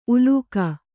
♪ ulūkha